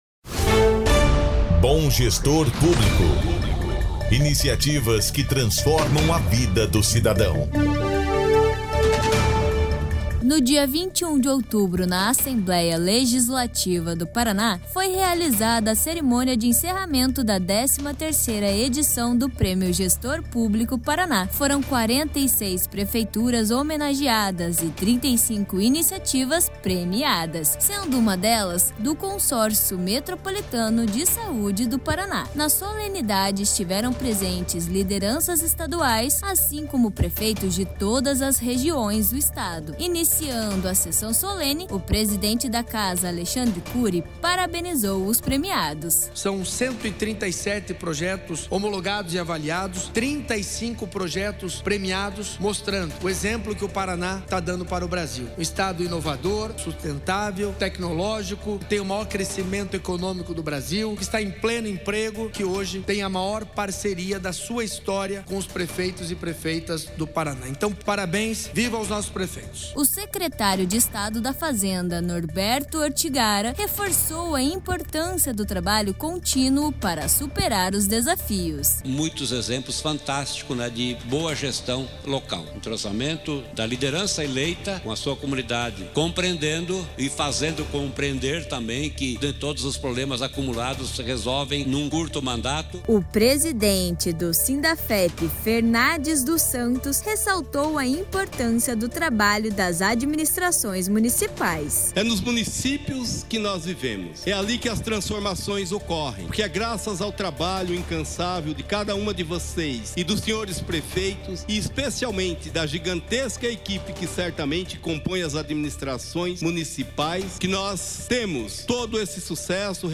Nesta terça-feira (21), foi realizada a Cerimônia de Encerramento da 13ª edição do Prêmio Gestor Público Paraná (PGP-PR). No evento que foi realizado na Assembleia Legislativa do Paraná (Alep), 35 iniciativas da gestão pública municipal foram premiadas.
Cerimônia-de-Encerramento-Solenidade-v2.mp3